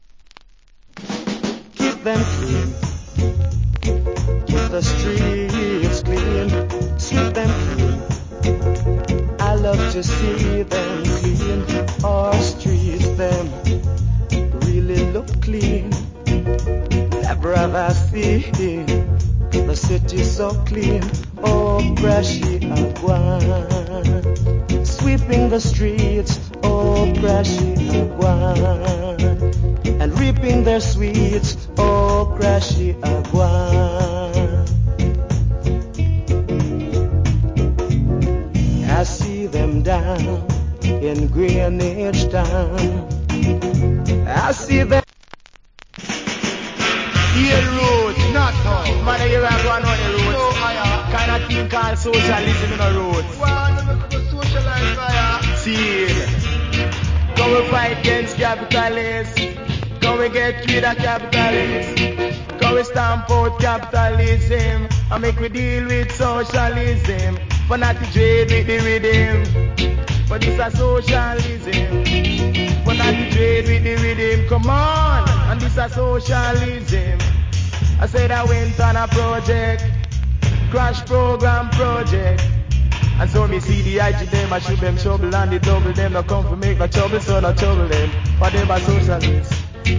Nice Roots.